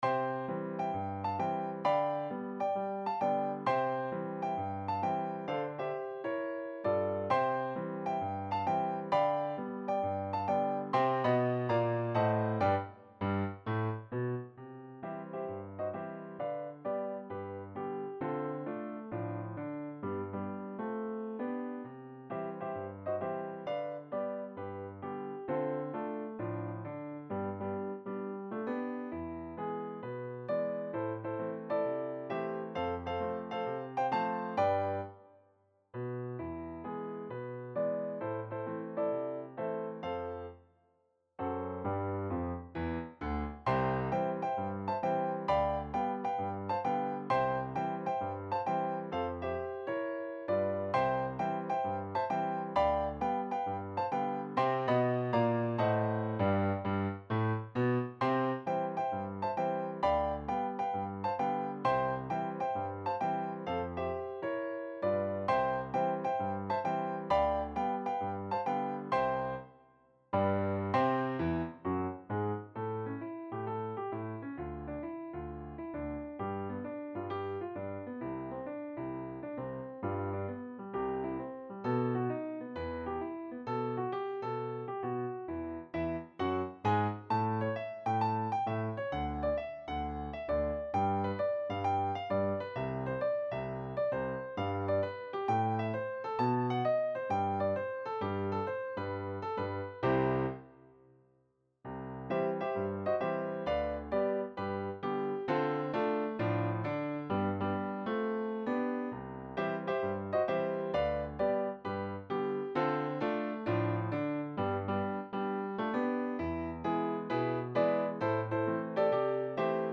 solo piano version
1920s-styled